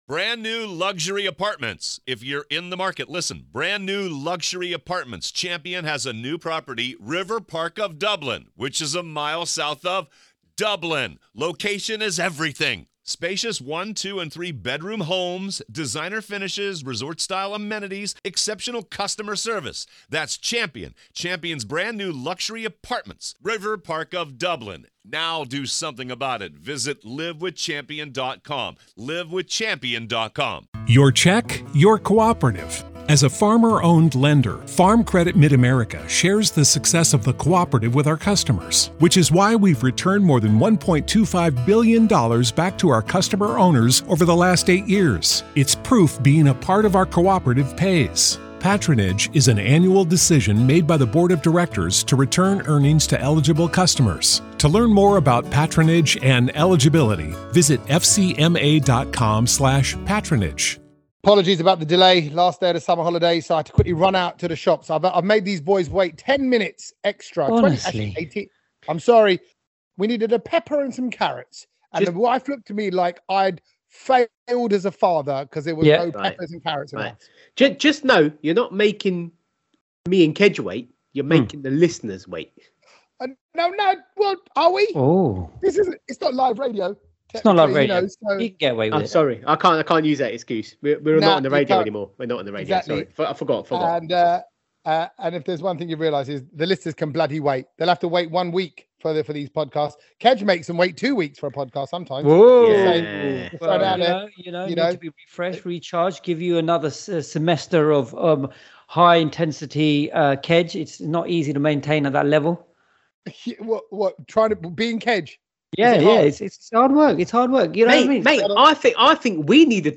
Yeah, we’re just three guys reflecting our “British Bharatian" roots!